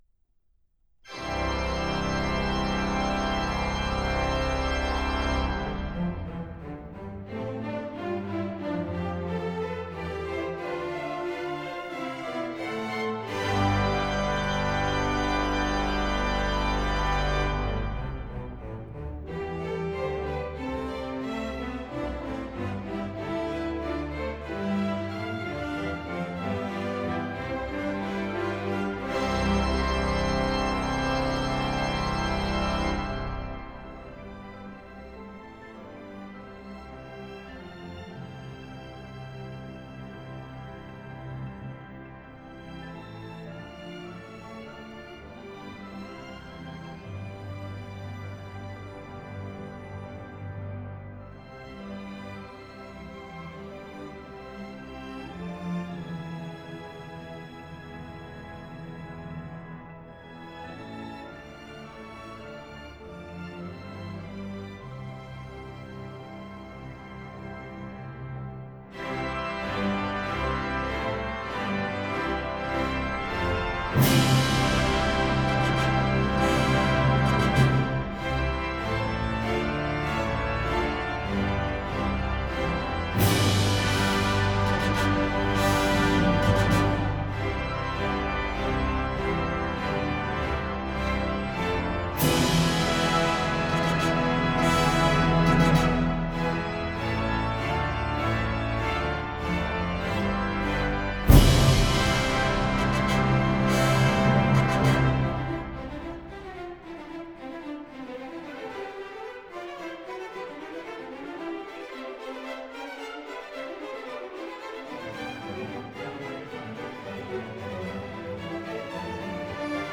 DALLAS SYMPHONY Saint-Saens "Organ" Symphony #3 in C Minor.